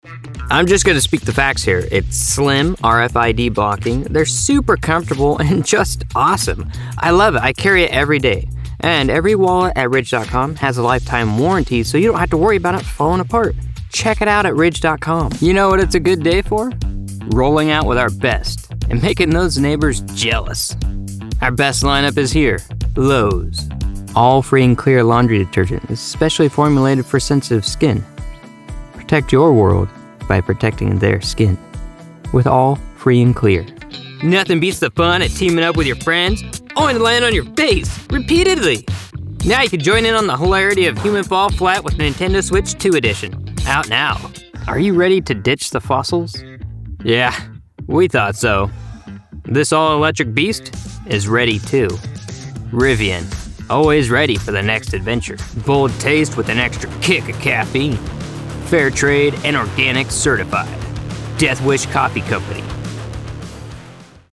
Current Commercial Demo
Recent commercial demo.
Commercial-Demo-March-2026-Final-MIX.mp3